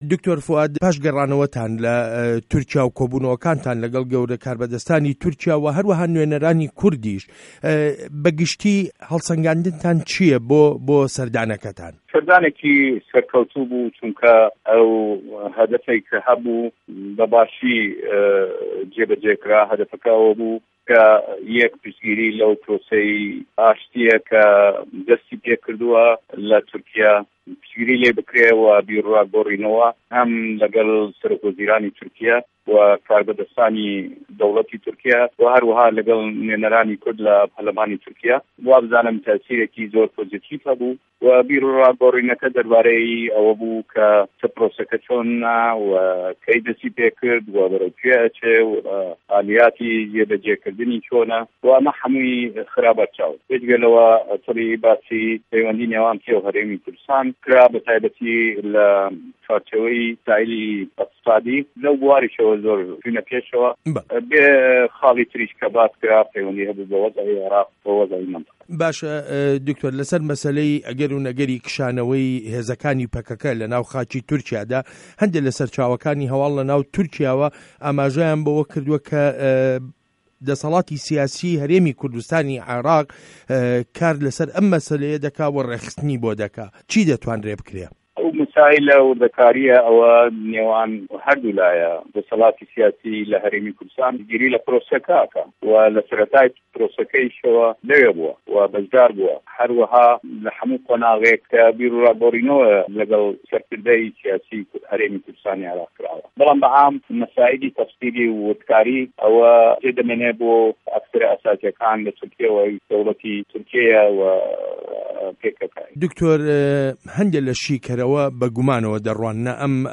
وتووێژ له‌گه‌ڵ دکتۆر فوئاد حسێن